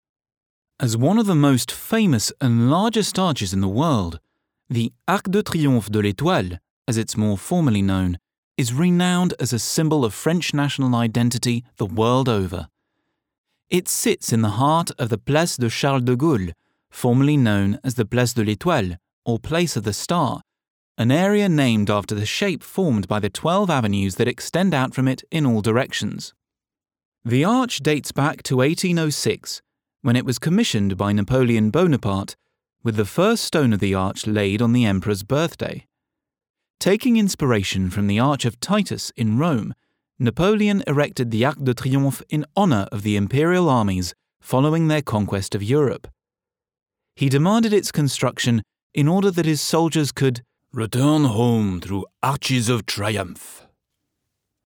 English (British)
Young, Natural, Versatile, Friendly, Corporate
Audio guide